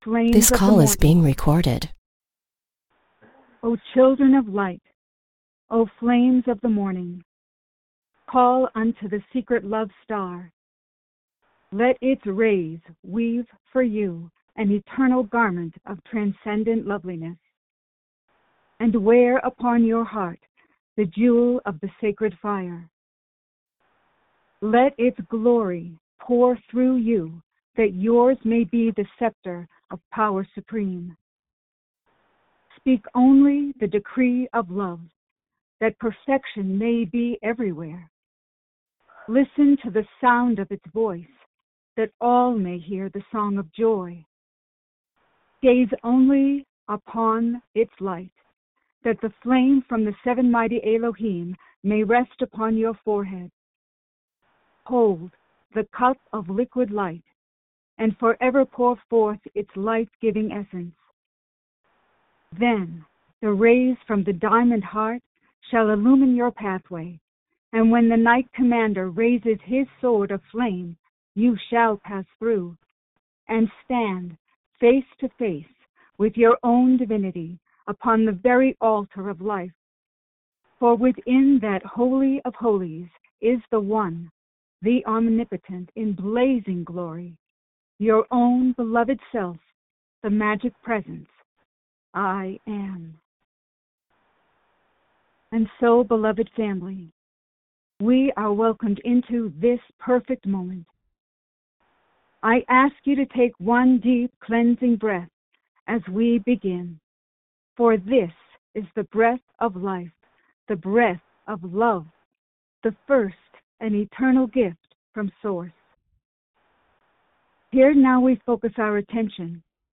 Audio Recording Meditation (Minute 00:00) Click HERE or on the AUDIO RECORDING link above, to join the group meditation with Master Saint Germain. Channeling